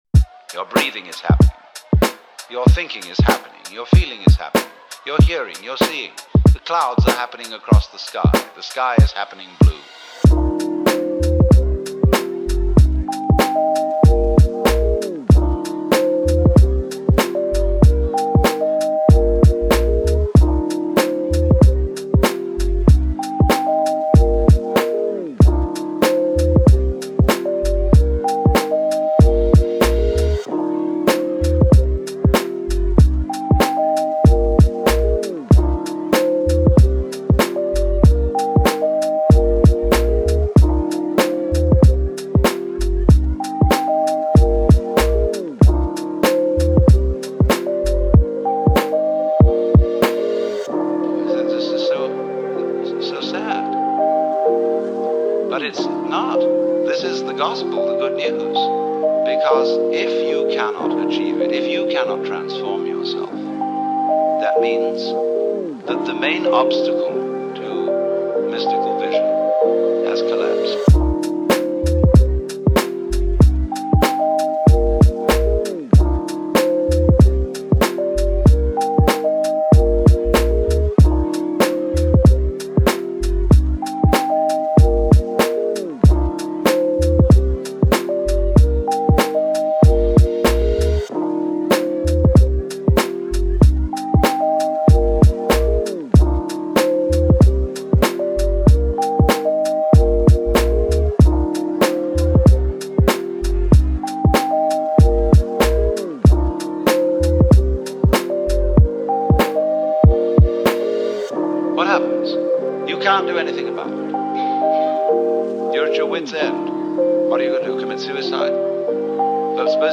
Music / 90s
beat rap chill guitar strings rb soul blues samples sampled sample chillhop chillwave beats lofi lofihiphop